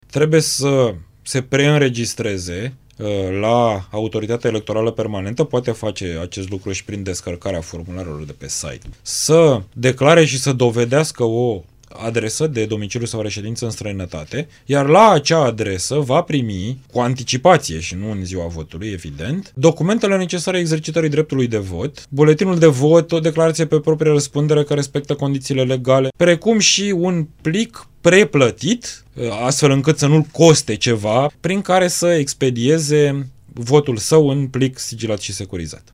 Invitat la Interviurile Europa FM, președintele Comisiei pentru Cod Electoral, deputatul liberal Mihai Voicu, a explicat că STS a testat deja scanerele încă de acum doi ani, iar acestea vor fi folosite la alegerile locale de anul viitor, dacă și Camera Deputaților votează această modificare a legii, aprobată deja de Senat.